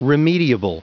Prononciation audio / Fichier audio de REMEDIABLE en anglais
Prononciation du mot : remediable